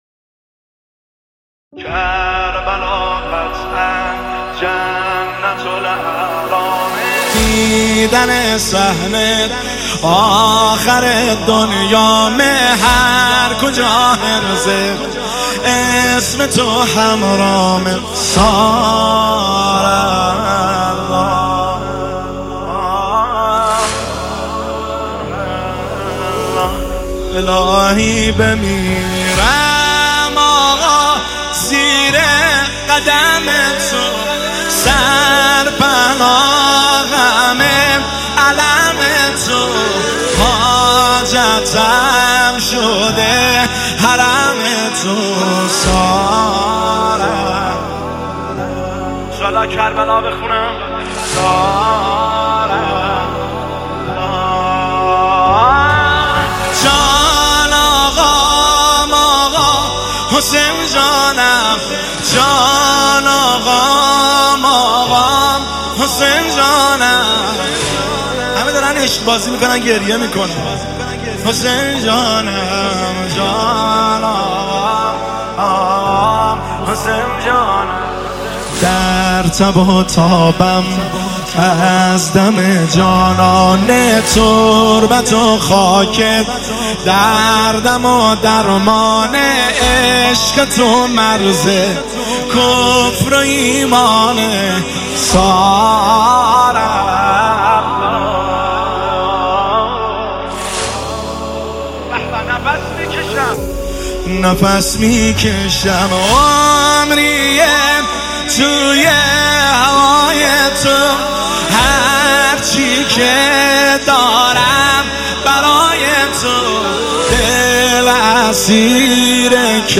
نوحه
مداحی پر شور